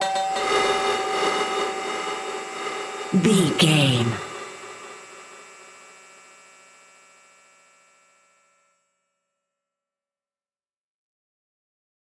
Low Night Creeping Stinger.
In-crescendo
Aeolian/Minor
tension
ominous
dark
eerie
creepy
synth
keyboards
ambience
pads